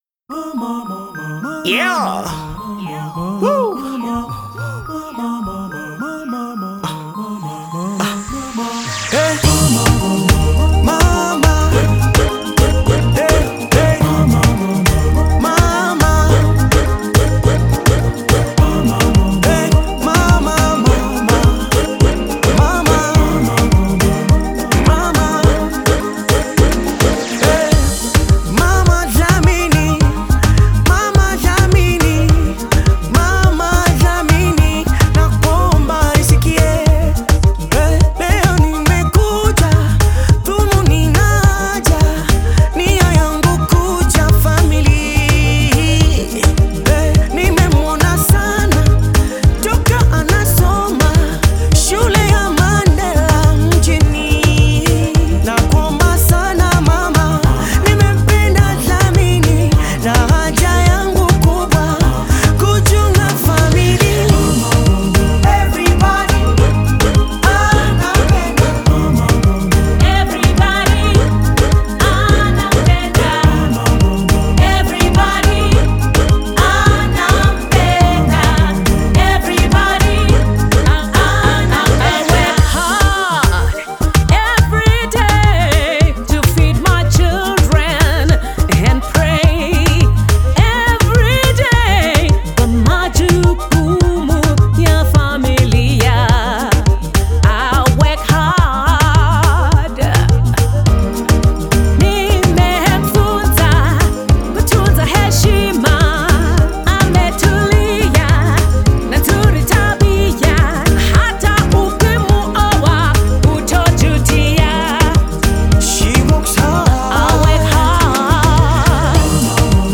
heartfelt Afro-Pop single